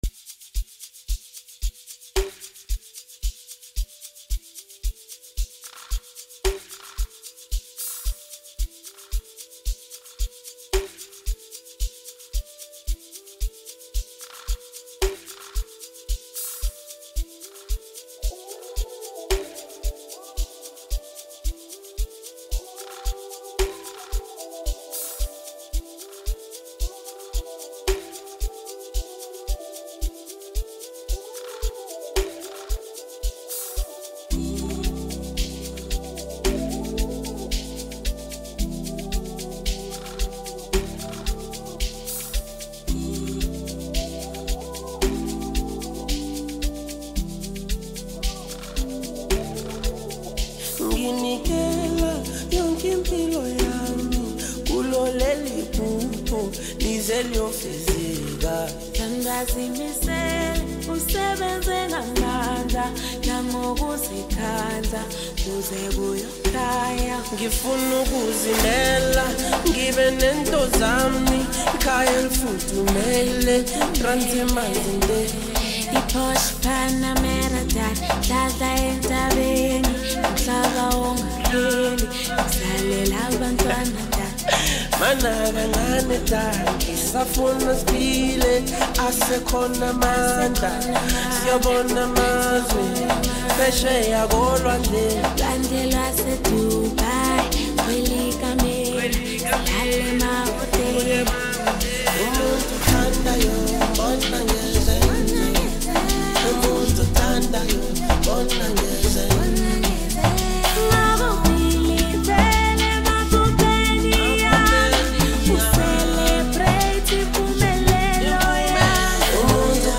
heartwarming